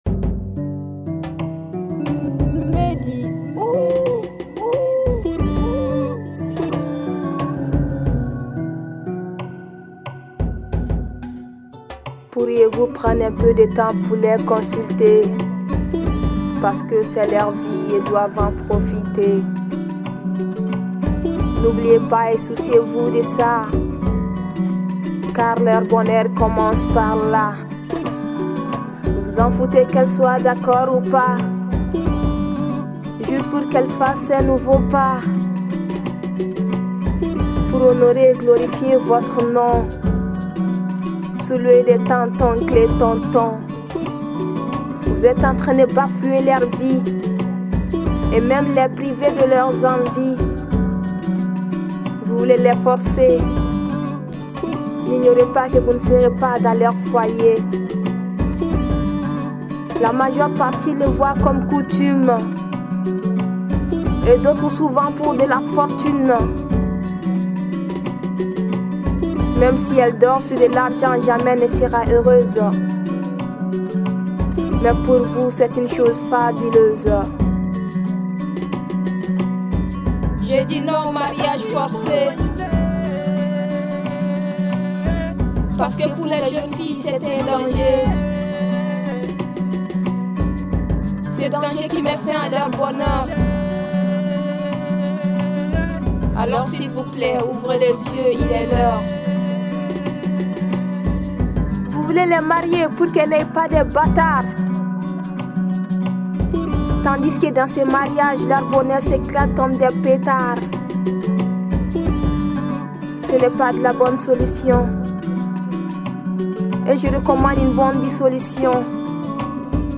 La jeune artiste slameuse malienne